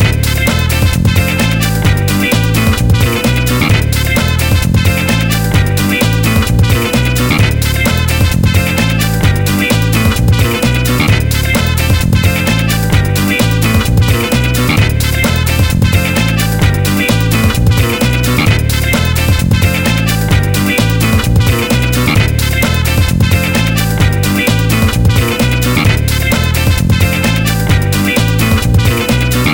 disco-inspired arrangement